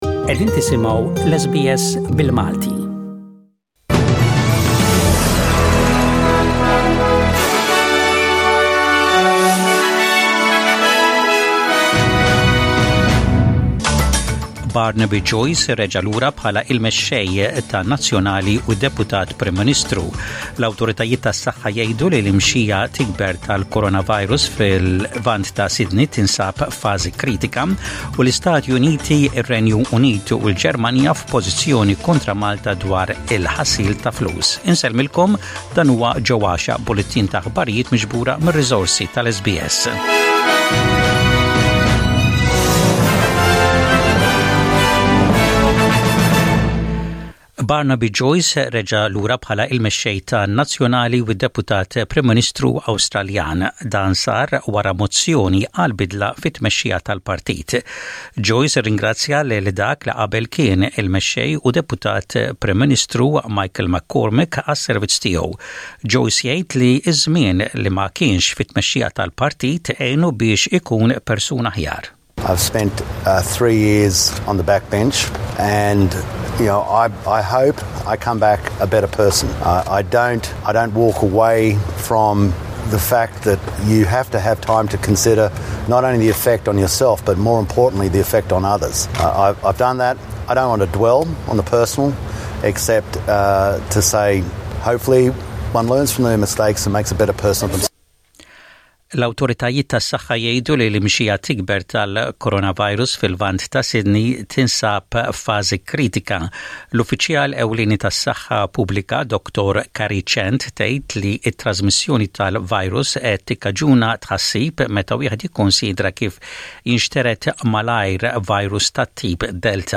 SBS Radio | Maltese News: 22/06/21